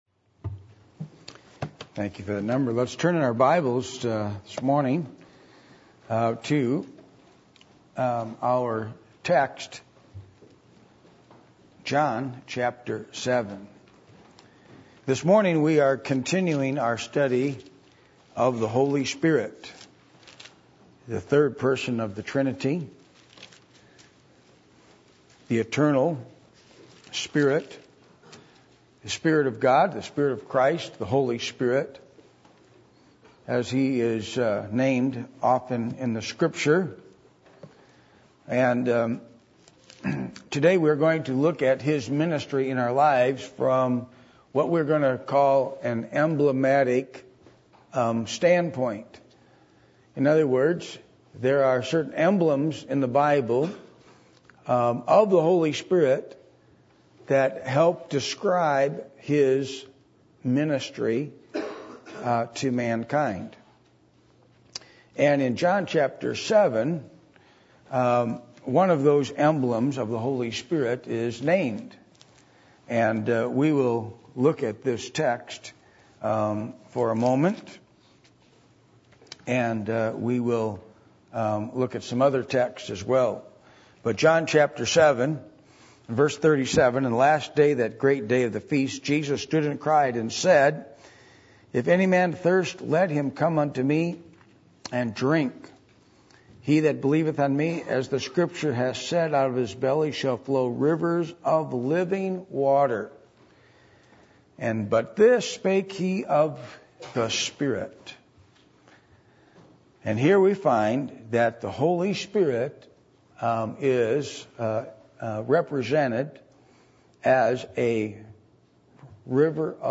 Passage: John 7:37-39 Service Type: Sunday Morning